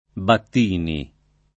[ batt & ni ]